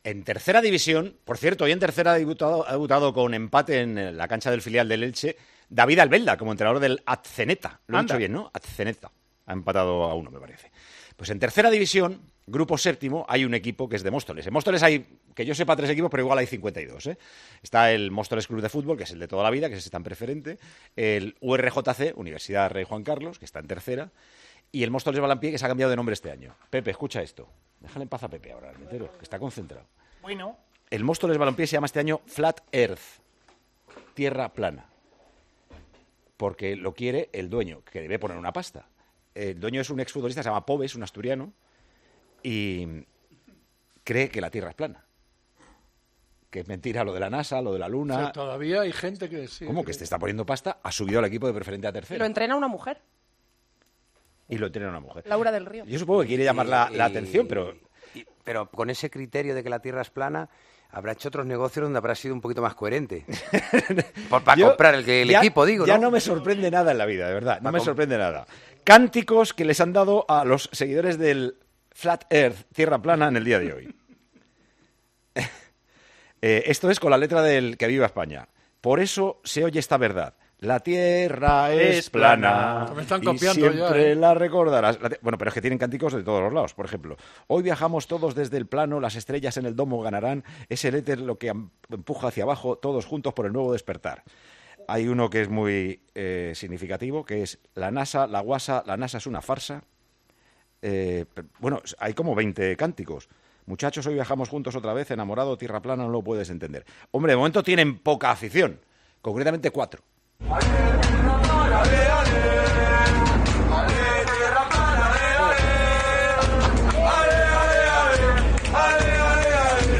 Los cánticos del Flat Earth FC, el equipo de Móstoles cuyo propietario cree que la Tierra es plana
Con Paco González, Manolo Lama y Juanma Castaño